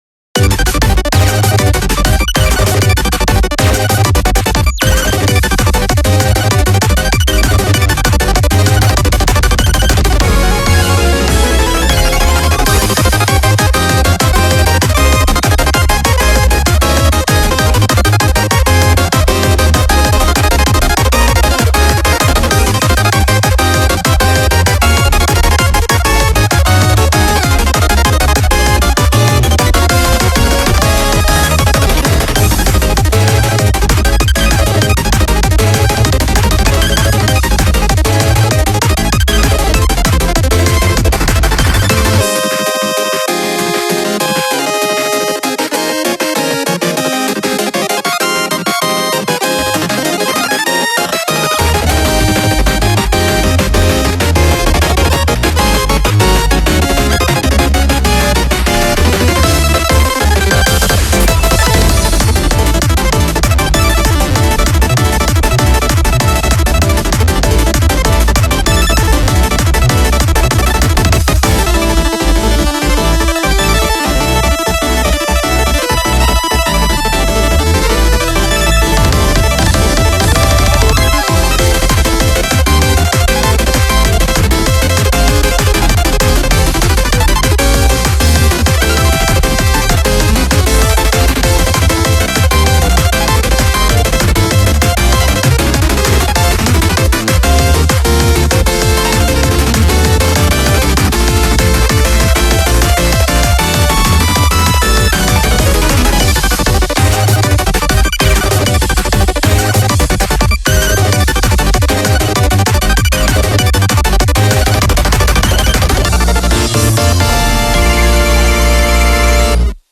BPM98-195
Audio QualityPerfect (High Quality)